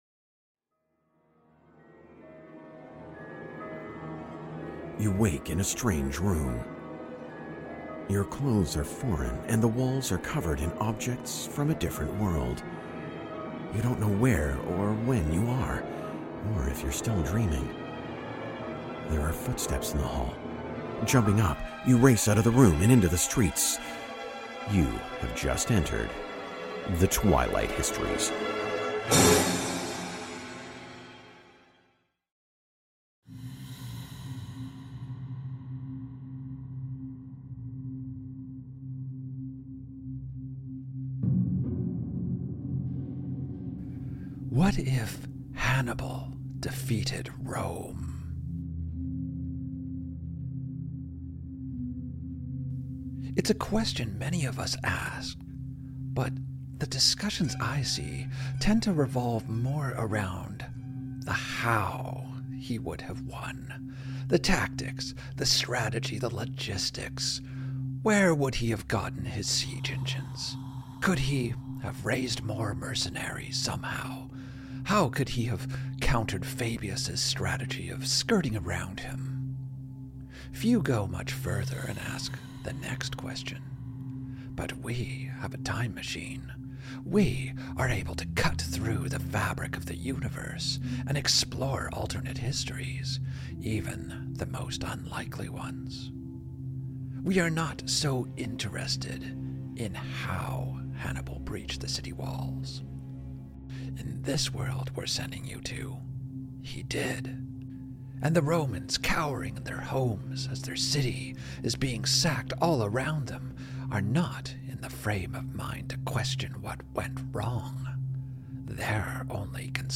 Step into an alternate history where Hannibal triumphs, and Carthaginian soldiers sack the city of Rome. Follow a Roman family as they barricade themselves inside their home, cowering from the invading forces. This immersive audio drama brings the terrifying chaos of the Second Punic War to life with stunning soundscapes and music.